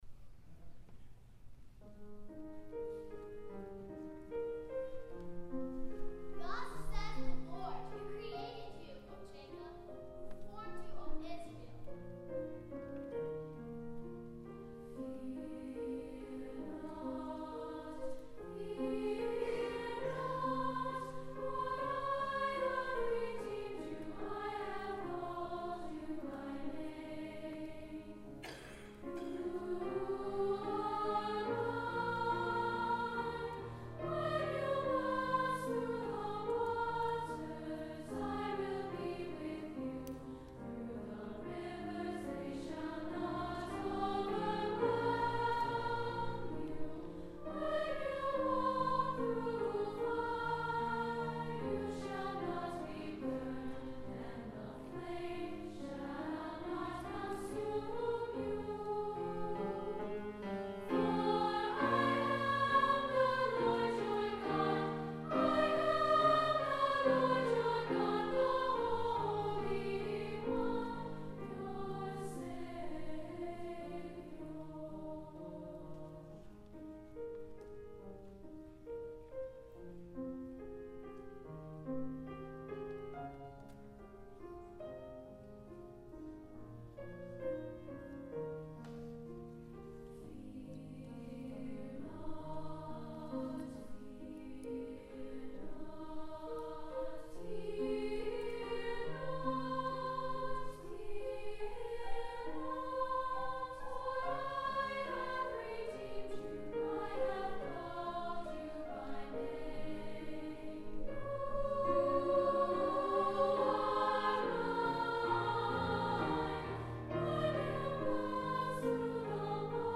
Parts for string accompaniment with piano for both editions.